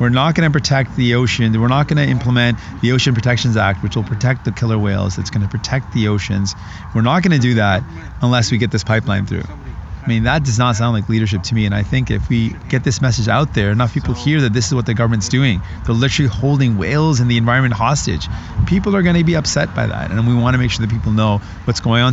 addressed a small crowd at Hecate Park